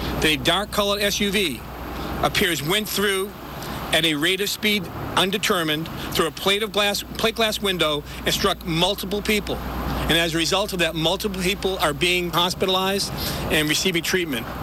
Plymouth County District Attorney Tim Cruz says there is an active criminal investigation into the incident.